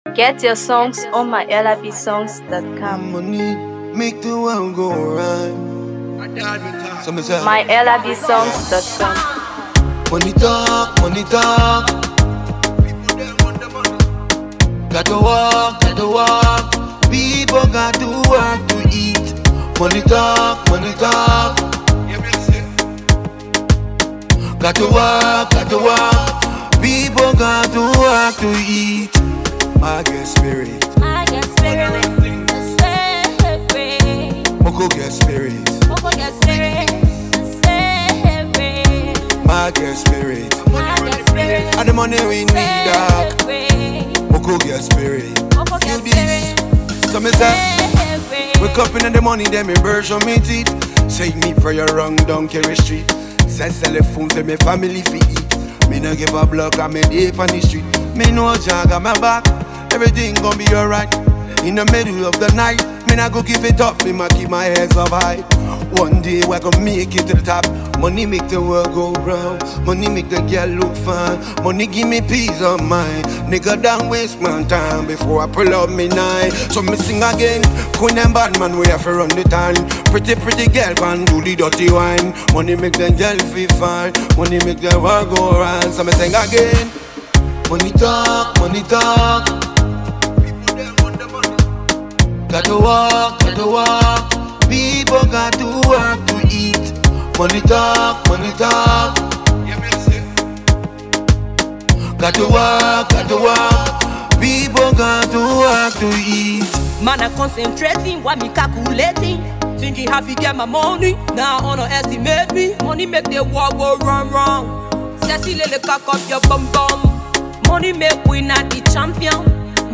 Afro PopMusic
smooth vocals and lyrical finesse
energetic delivery